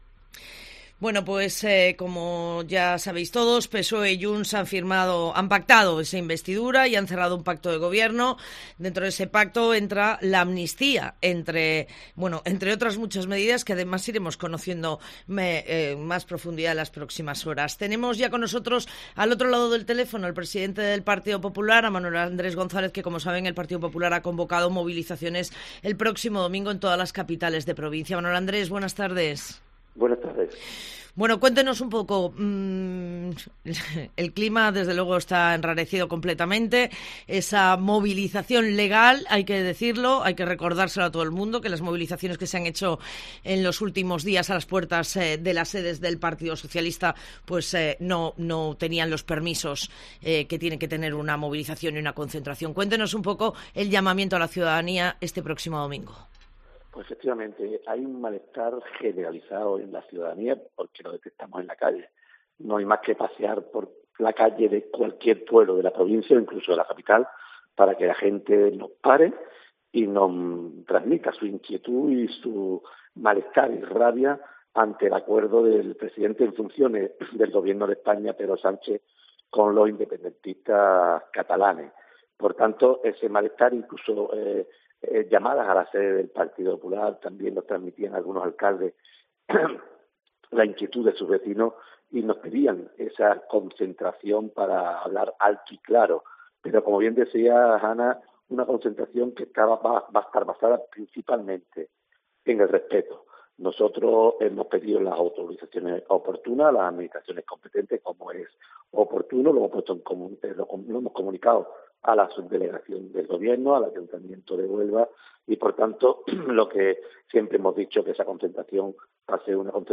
Manuel Andrés González, presidente del PP en Huelva
El presidente del PP en la provincia, Manuel Andrés González, ha hecho un llamamiento a la participación en los micrófonos de COPE.